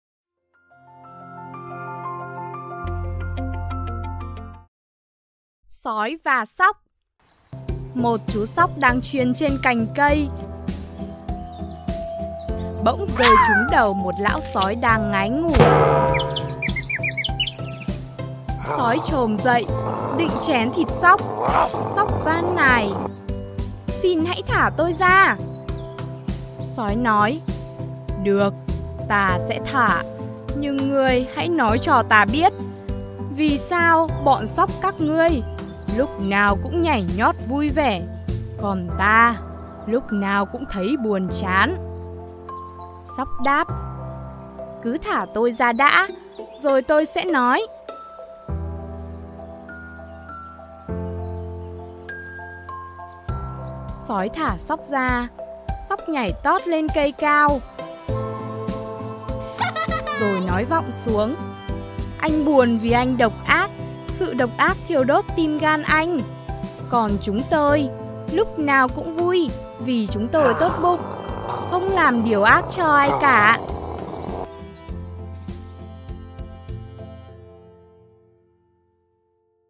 Sách nói | Sói và Sóc